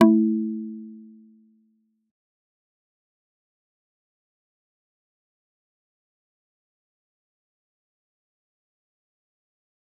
G_Kalimba-A3-mf.wav